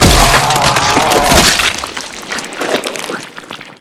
spacewormdie.wav